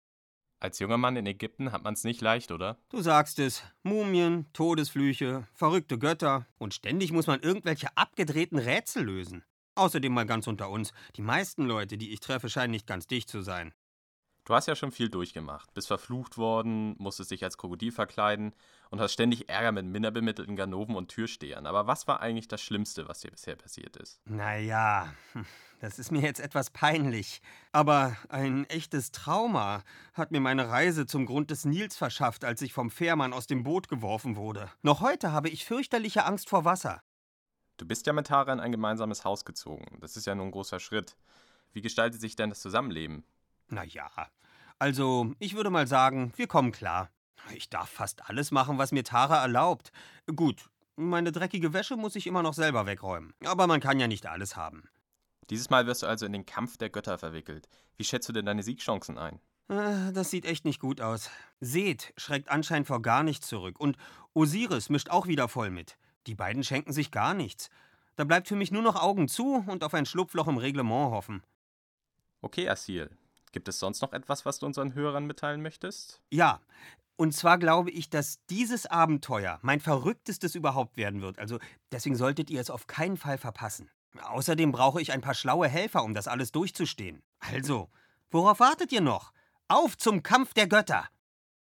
[Ankh 3 - Kampf der Götter Interview Assil]